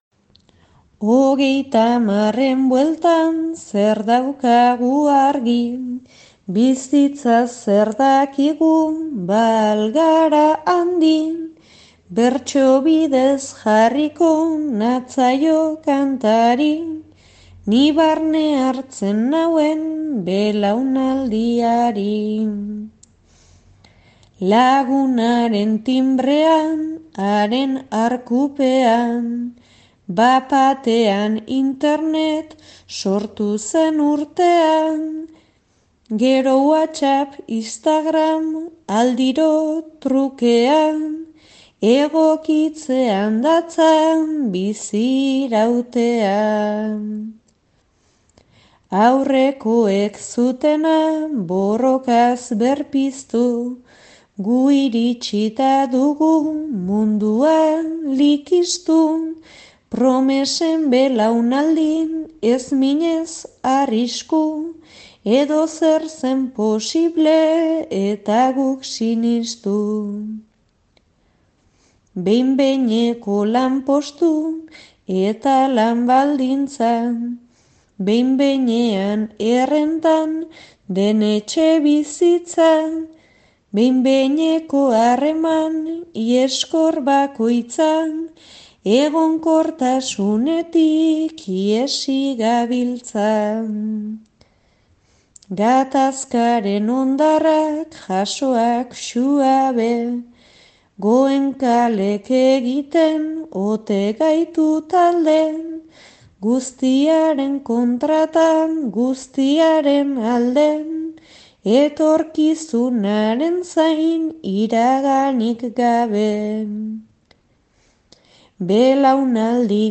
'Nire belaunaldiari' bertso sortarekin.